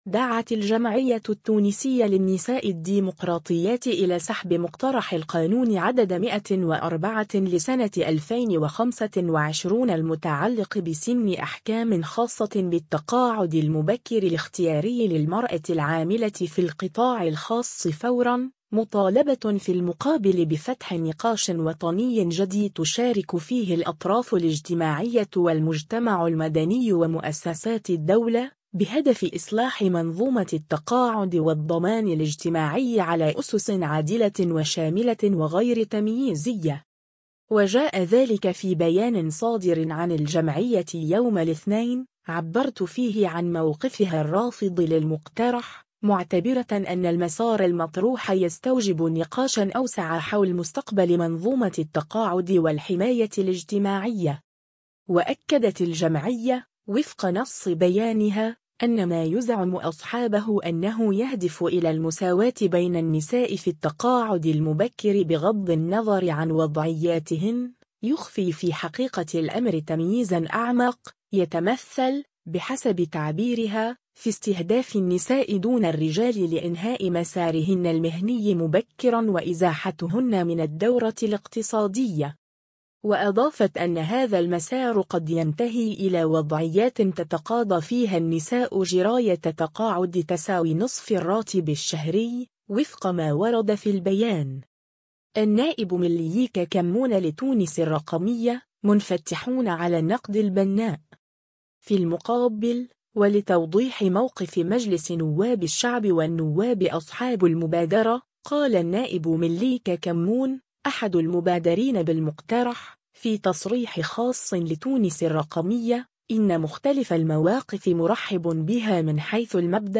في المقابل، ولتوضيح موقف مجلس نواب الشعب والنواب أصحاب المبادرة، قال النائب مليك كمّون، أحد المبادرين بالمقترح، في تصريح خاص لـ“تونس الرقمية”، إن مختلف المواقف “مرحب بها” من حيث المبدأ، في إطار التفاعل الإيجابي ومزيد تجويد المحتوى.